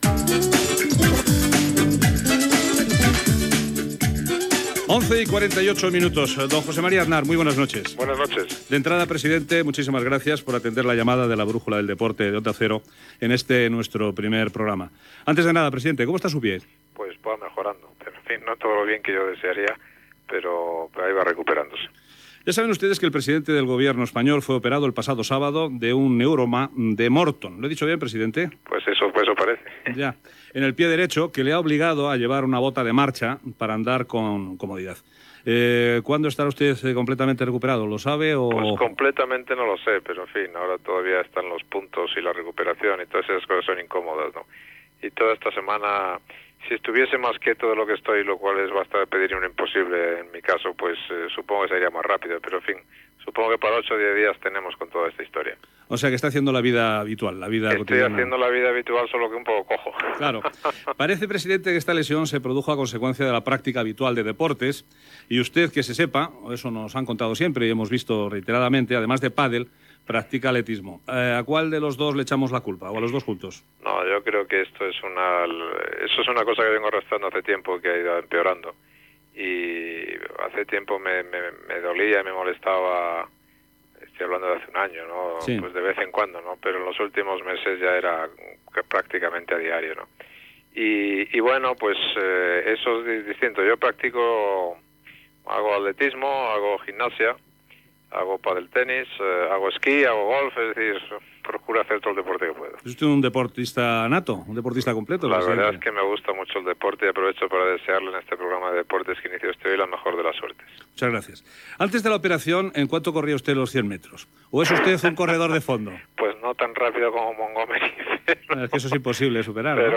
Hora, entrevista telefònica al president del Govern espanyol, José María Aznar.